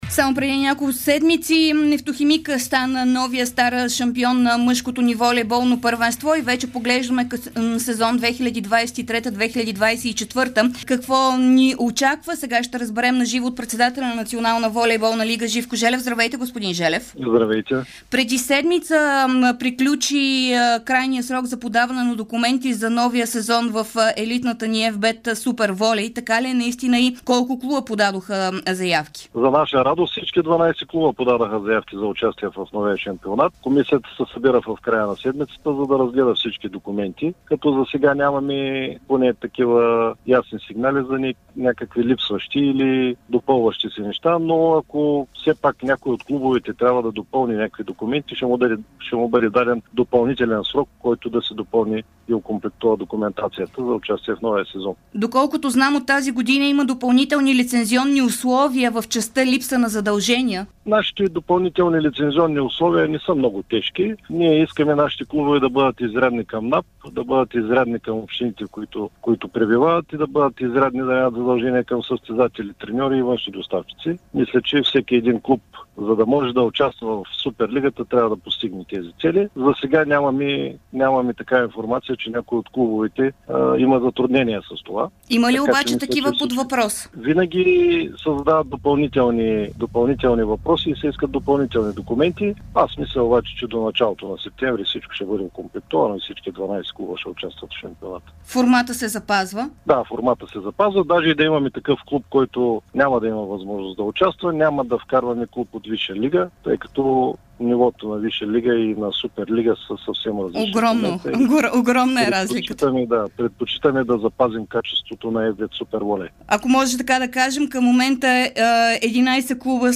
в ефира на Дарик радио